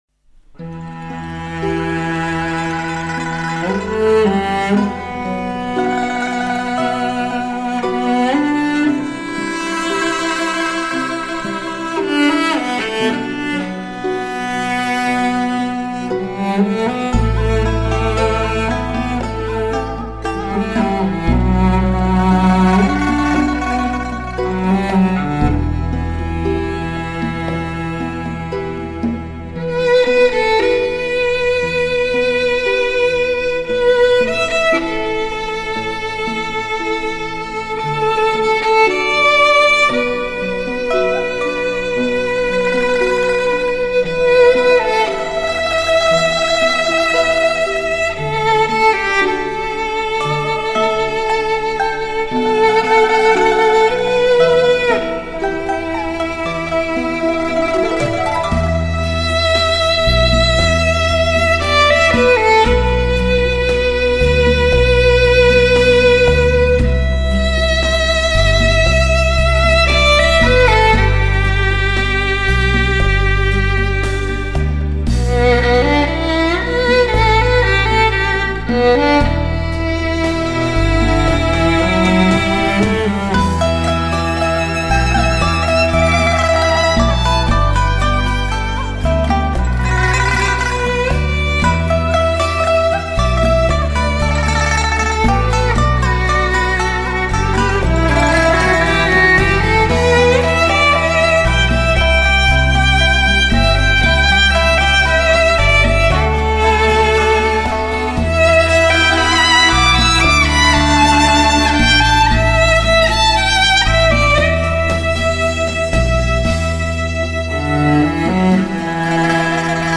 聆听雪域高原的唯美旋律，感受发自肺腑的动人旋律
小提琴演奏音色优美，音质细腻传真，极富歌唱性